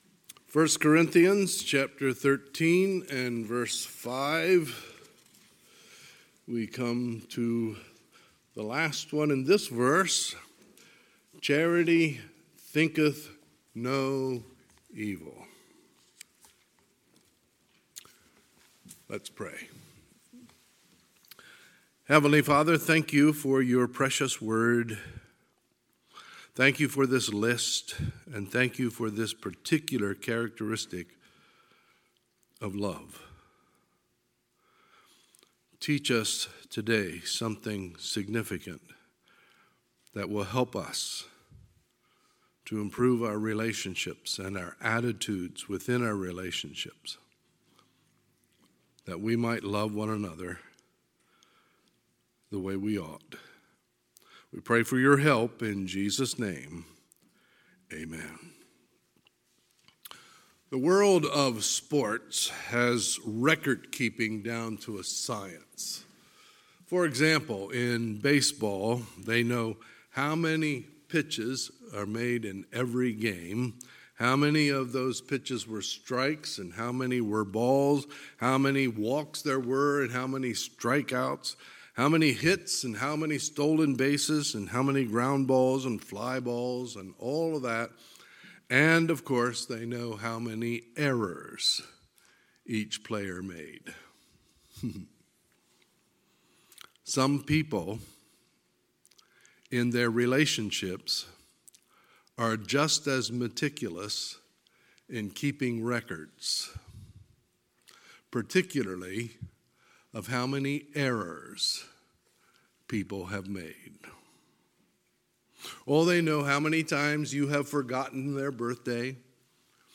Sunday, September 5, 2021 – Sunday AM
Sermons